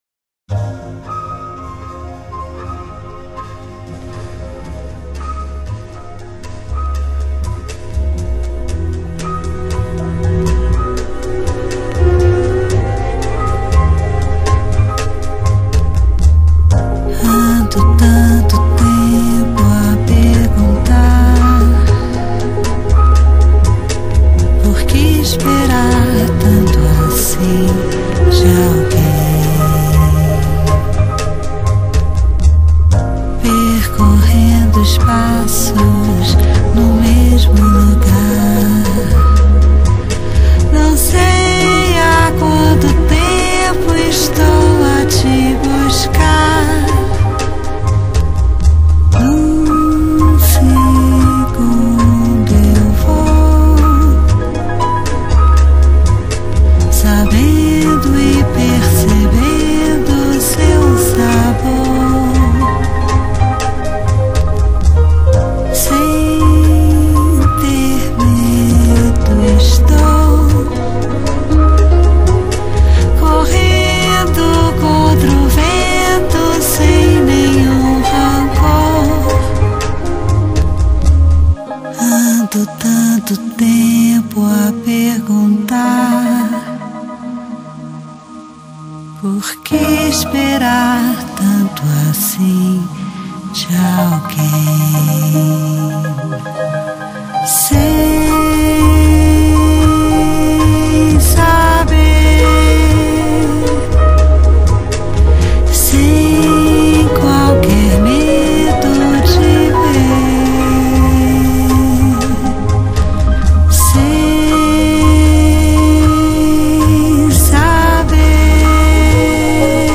■Ladies' Jazz■